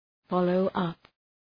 Προφορά